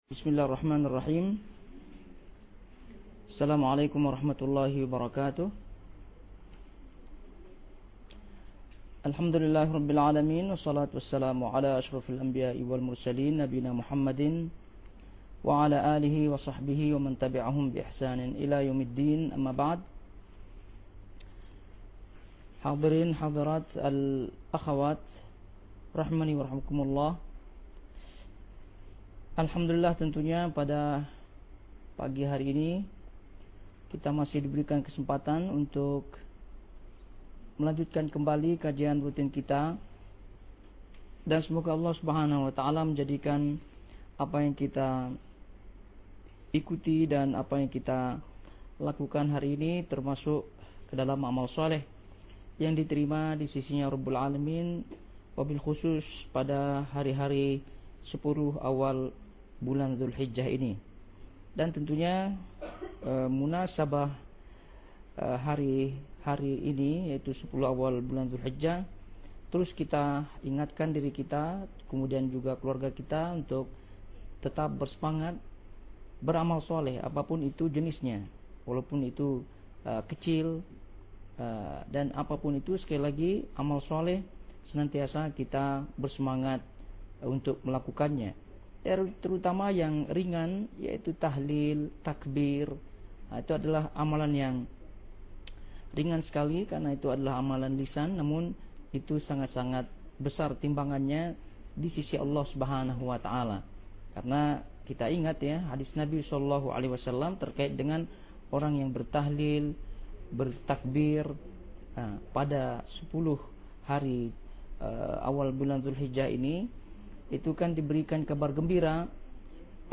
Kajian Ummahat Doha – Senin Pagi Membahas